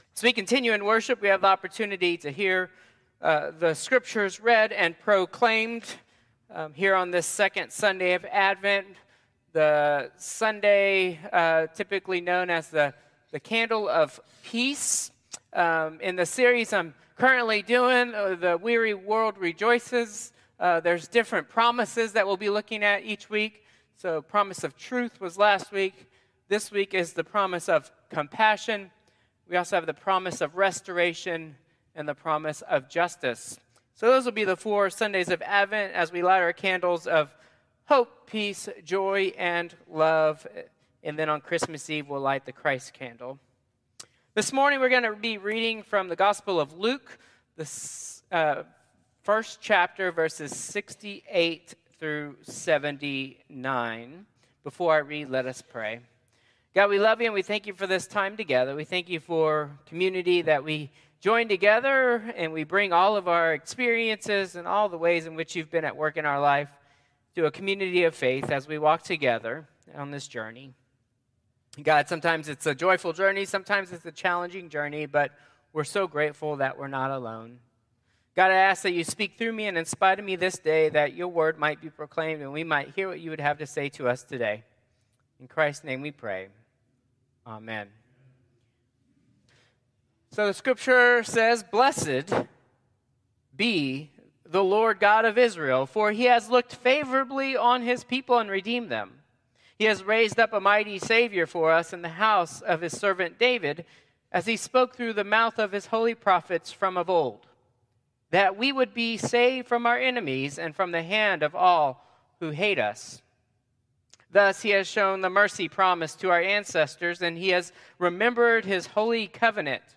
Traditional Service 12/8/2024